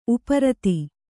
♪ uparati